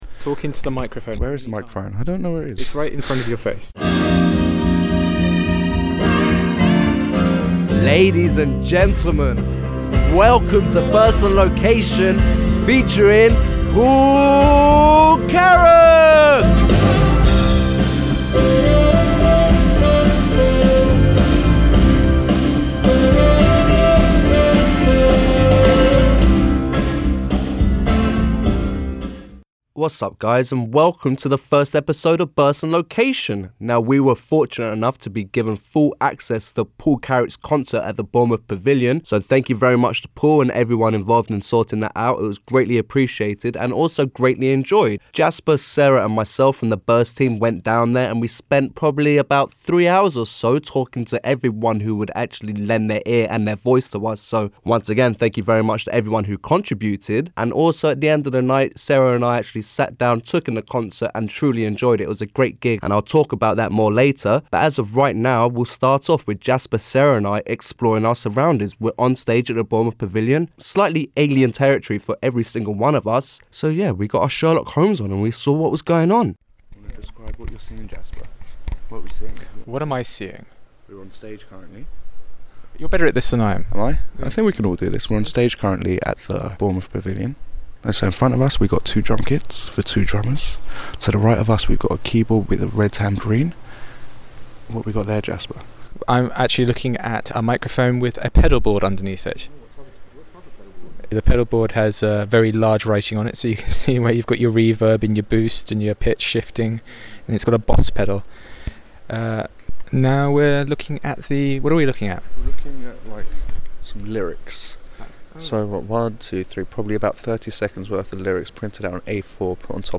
Listen to how three wily students spent the day interviewing band members, crew and even the staff at the ever hospitable Bournemouth Pavilion.